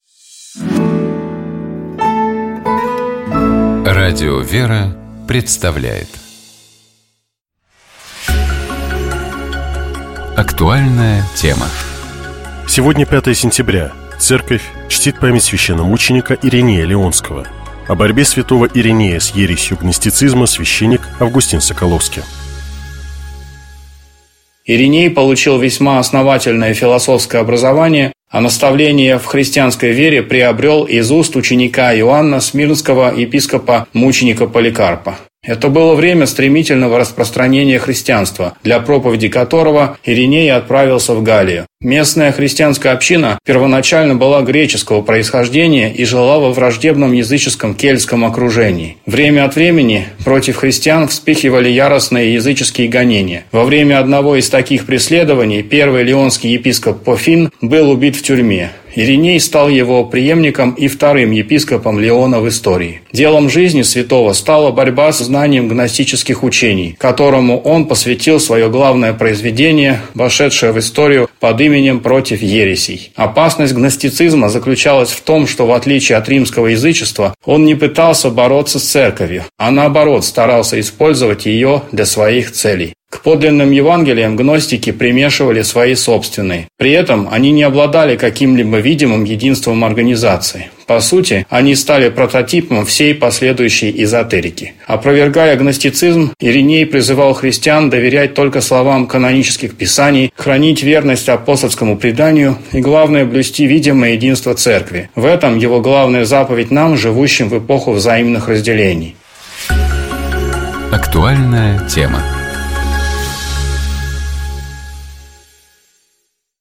У нас в гостях были пилоты вертолетного поискового отряда «Ангел»